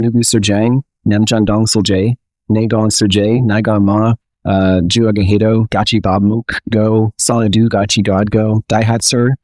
먼저 MetaVoice 의 최익현씨 성대모사를 들어보자
지금까지 HuggingFace 극단의 외국인 배우의 한국영화 명대사 열연을 보셨습니다.